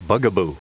Prononciation du mot bugaboo en anglais (fichier audio)
Prononciation du mot : bugaboo